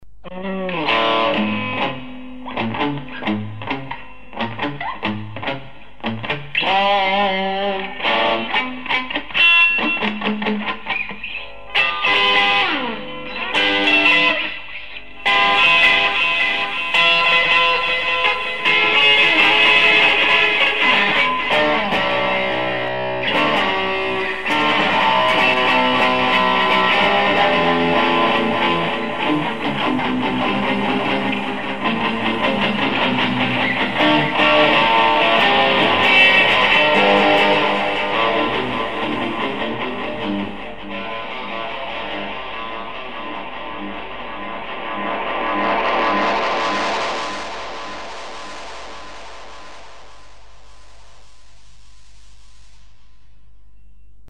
Bass
Drums
Guitar